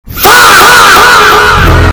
fahh but louder very loud Meme Sound Effect
fahh but louder very loud.mp3